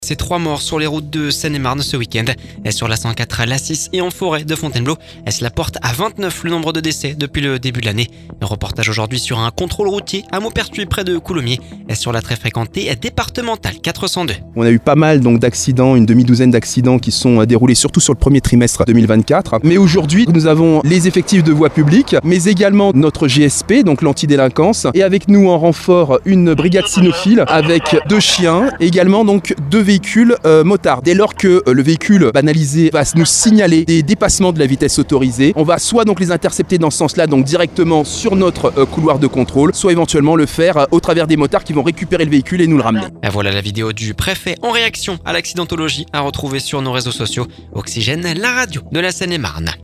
Reportage sur un contrôle routier.
A Mauperthuis, près de Coulommiers, sur la très fréquentée départementale 402.